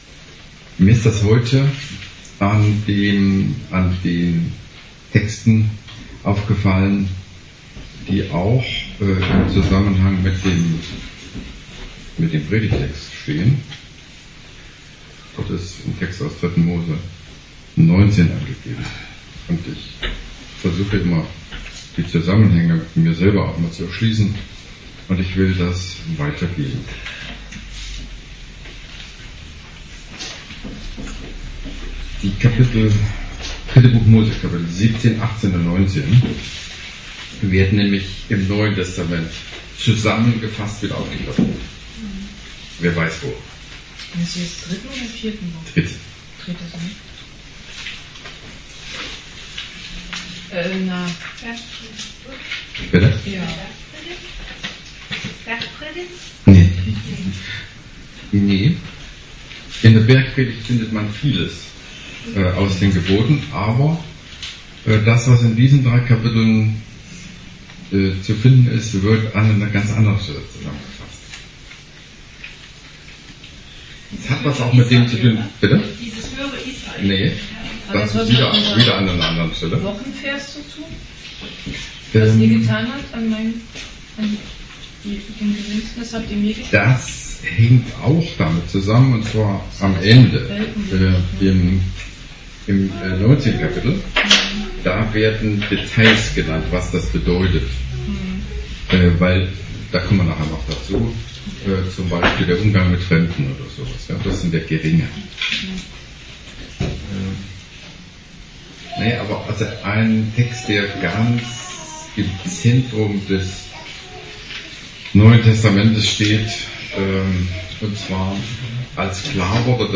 Predigt über 3. Mose 17 – 19